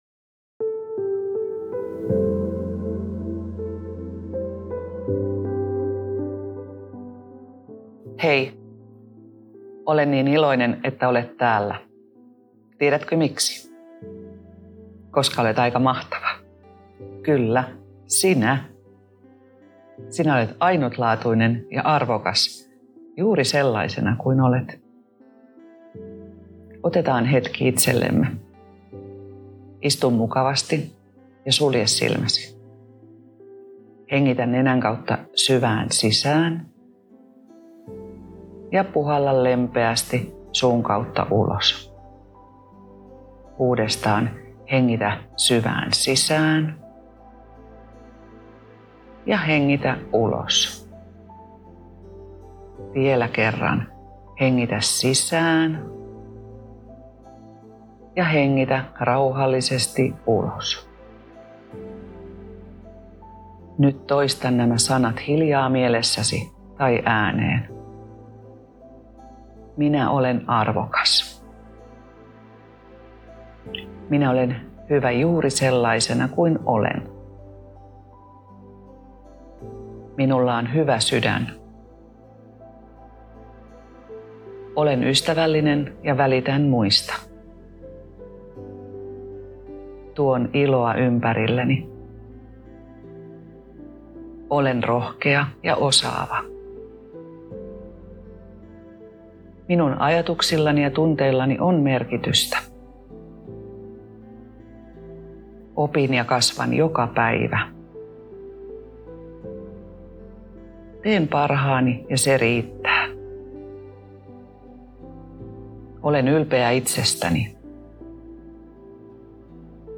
The current audio is a gentle meditation with self-love positive affirmations, accompanied with nicely designed posters.
Meditation-with-self-love-affirmations-Finnish.mp3